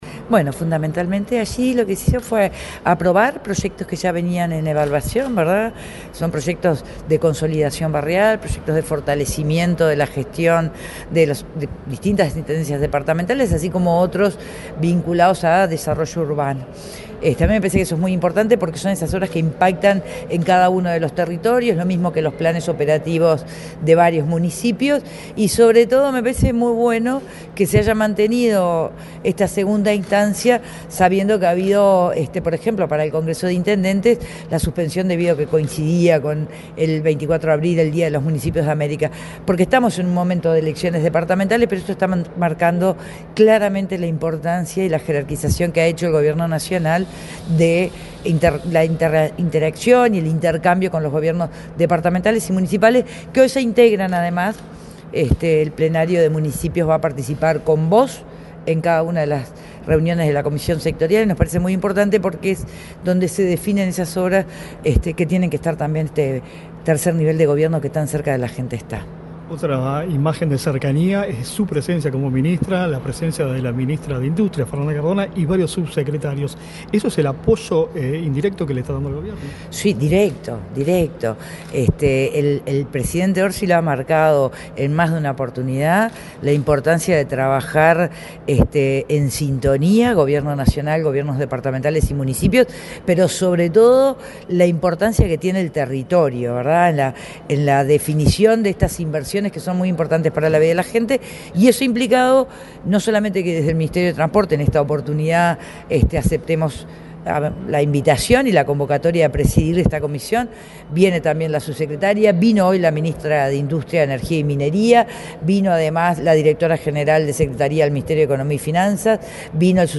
Entrevista a la ministra de Transporte, Lucía Etcheverry
Entrevista a la ministra de Transporte, Lucía Etcheverry 28/04/2025 Compartir Facebook X Copiar enlace WhatsApp LinkedIn La ministra de Transporte, Lucía Etcheverry, dialogó con Comunicación Presidencial, este lunes 28 en la Torre Ejecutiva, luego de encabezar la segunda reunión de la Comisión Sectorial de Descentralización, convocada por este Gobierno.